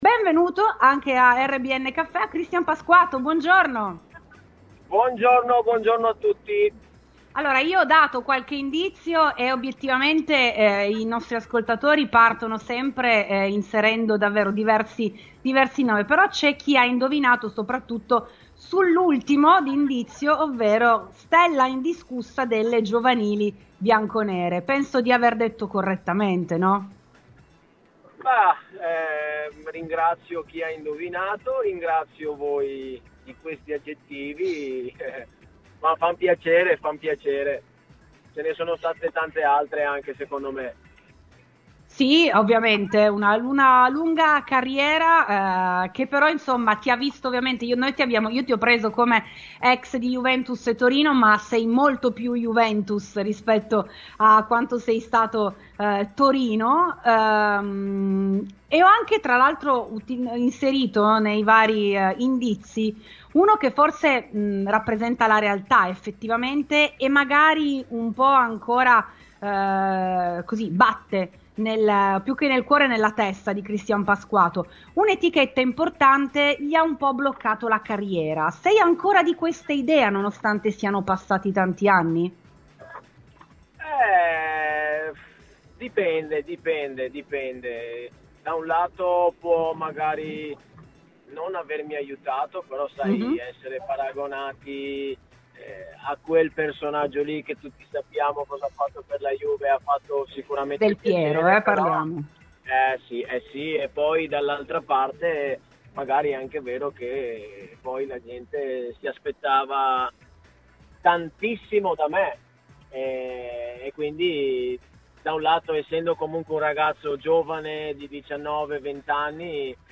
è intervenuto questa mattina durante RBN Cafè su Radiobianconera